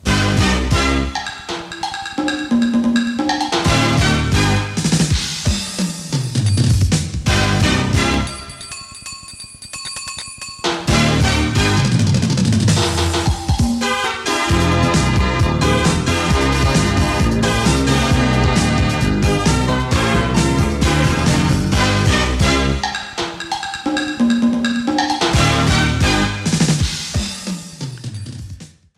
Sintonia musical del programa.